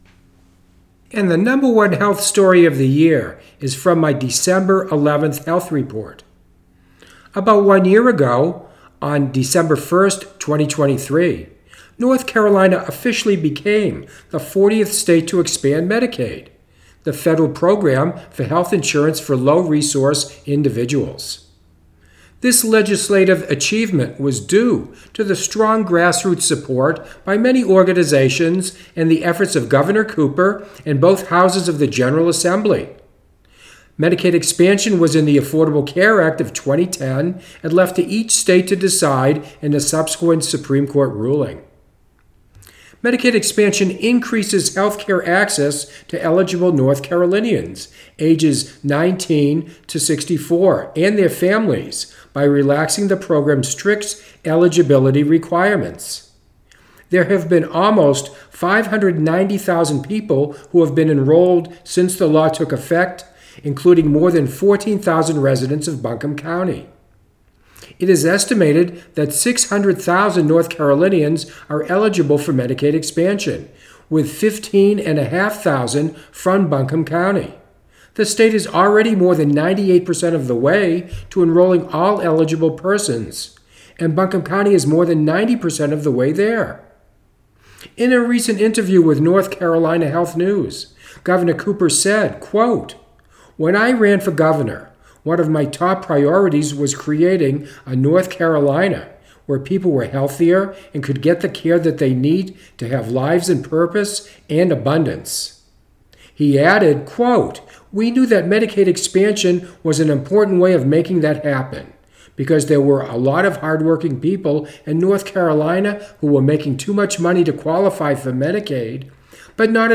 #1 Health report for 2024: The first anniversary of Medicaid expansion in North Carolina